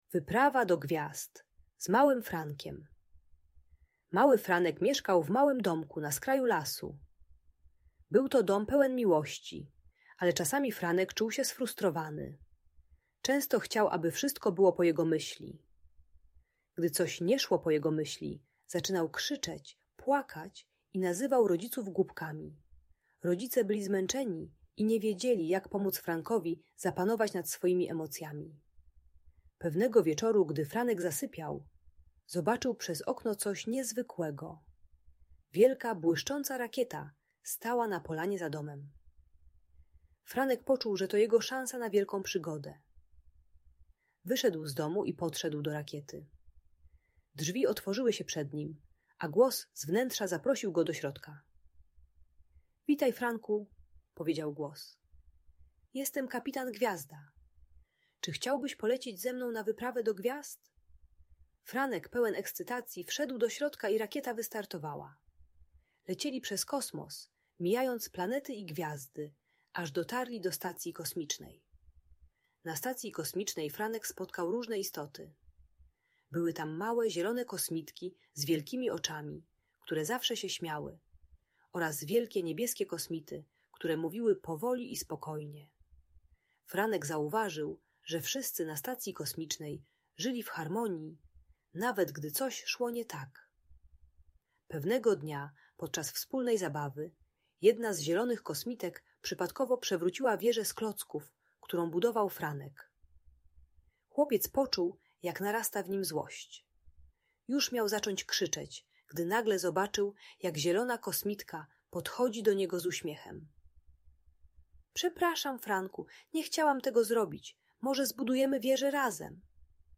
Ta audiobajka o złości i agresji uczy techniki "magicznego hamulca" - zatrzymania się, głębokiego oddechu i liczenia do 10 zanim dziecko zareaguje krzykiem.